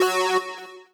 dimrainsynth.wav